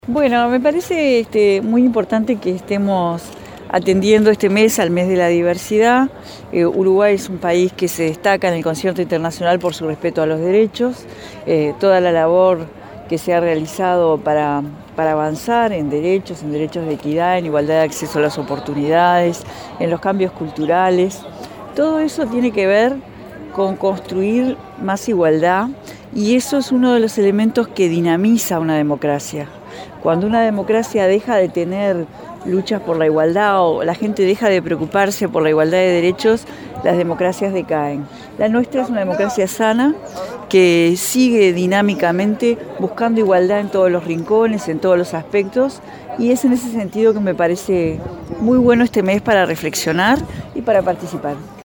“Pertenezco a un Gobierno de cercanía que lucha por los derechos y la igualdad de oportunidades para todas las personas en todos los planos”, enfatizó la ministra Carolina Cosse, en la apertura del encuentro “Uruguay LGBT 2018”. Destacó que “el país, en los últimos años, ha vivido revoluciones productivas y de derechos” y añadió que “las democracias decaen cuando se deja de luchar por la igualdad”.